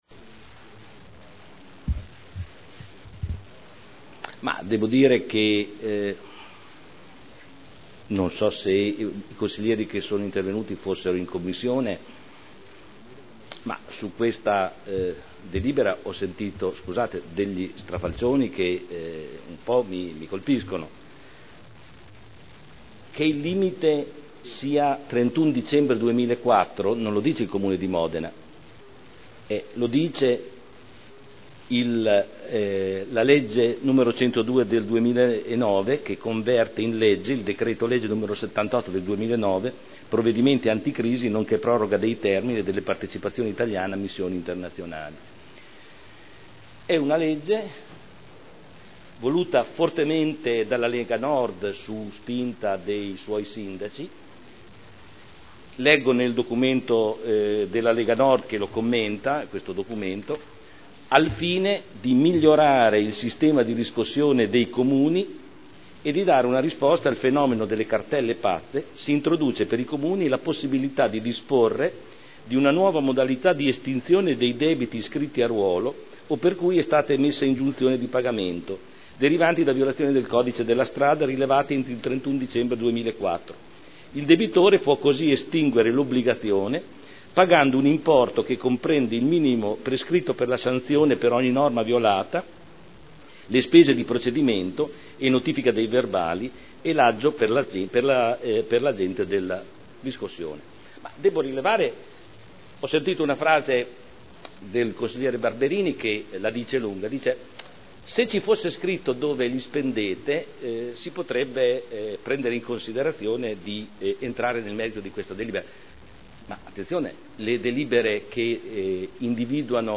Giorgio Pighi — Sito Audio Consiglio Comunale
Seduta del 20/12/2010. Dibattito su delibera: Definizione agevolata dei debiti derivanti da sanzioni relative a verbali di accertamento di violazioni al codice della strada elevati dal 1.1.2000 al 31.12.2004 (art. 15 comma 8 quinquiesdecies legge 3.8.2009 n. 102) (Commissione consiliare del 14 dicembre 2010)